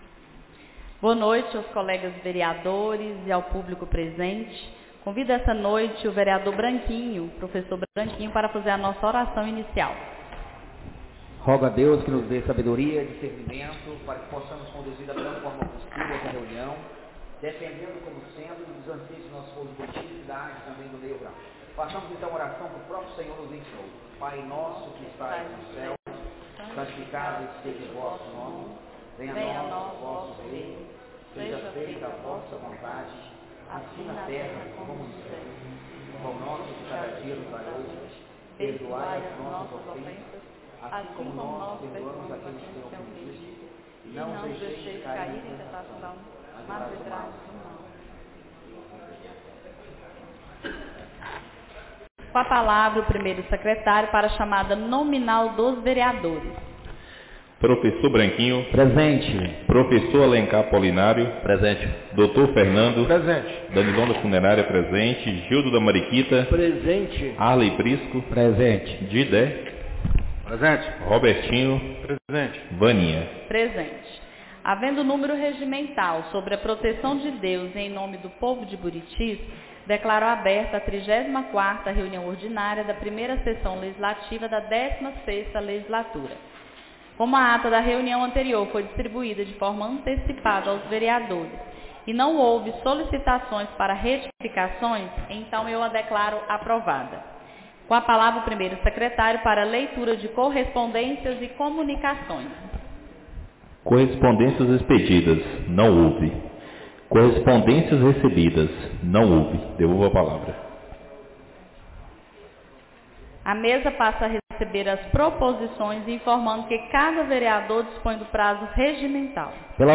34ª Reunião Ordinária da 1ª Sessão Legislativa da 16ª Legislatura - 06-10-25